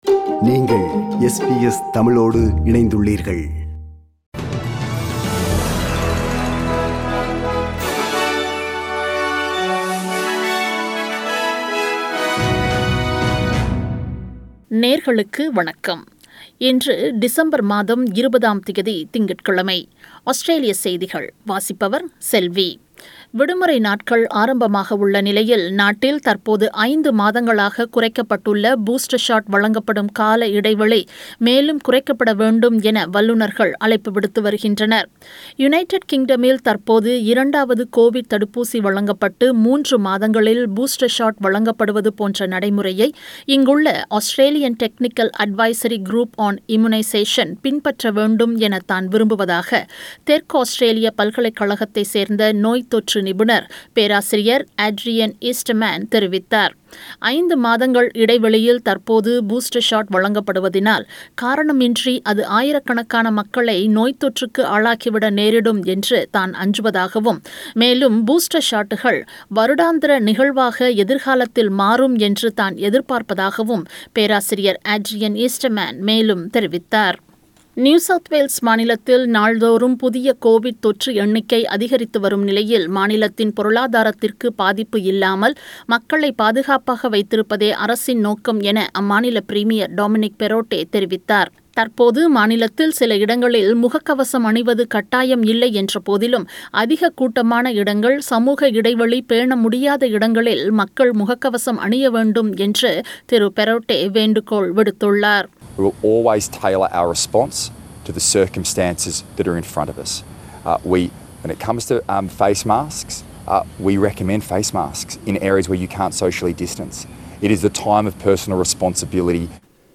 Australian News: 20 December 2021 – Monday